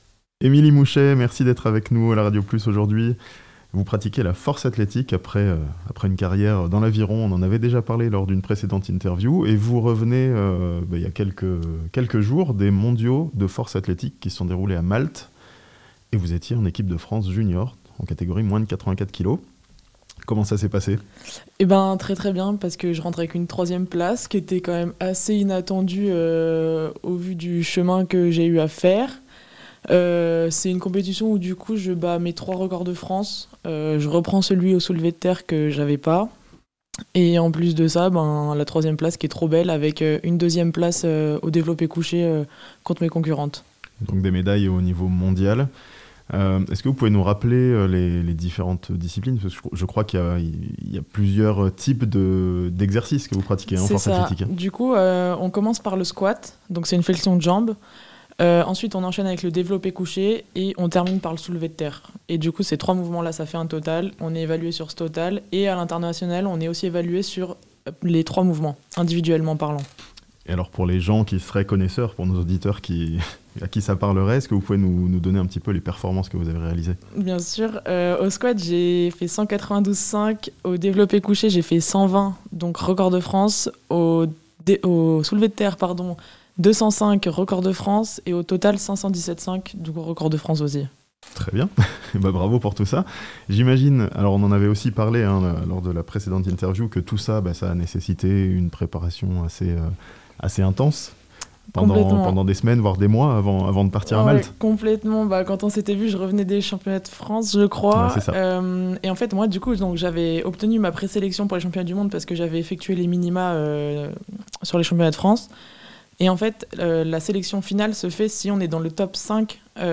médaillée de bronze aux derniers mondiaux de force athlétique, au micro La Radio Plus